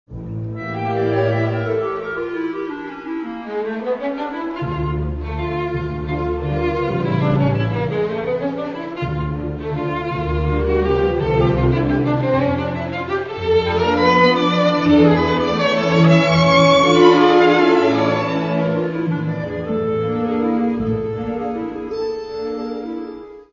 Каталог -> Класична -> Опера та вокал